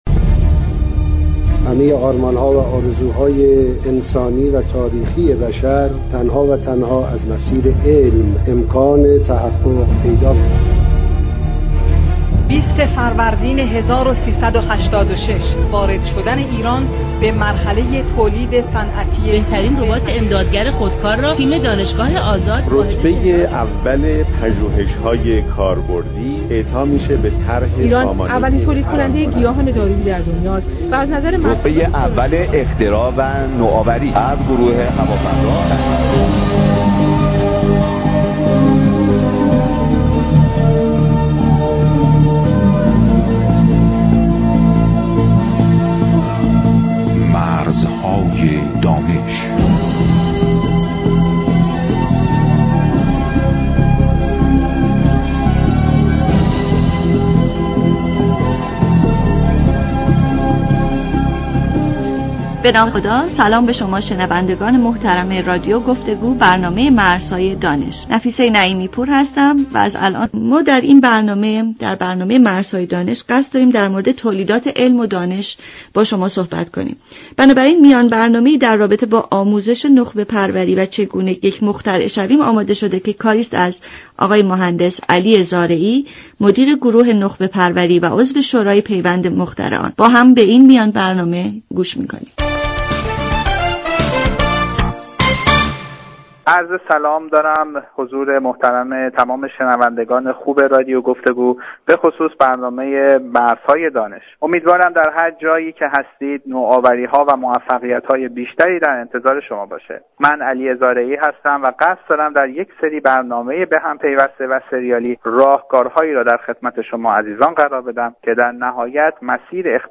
کسانی که مایلند راهکارهای رسیدن به اختراع را به صورت صوتی بشنوند می توانند هر هفته برنامه مرزهای دانش که از رادیو گفتگو پخش می شود دنبال نمایند همچنین در سایت اینترنتی موسسه رشد خلاقیت نوآوران جوان این برنامه ها برای دانلود قرار می گیرد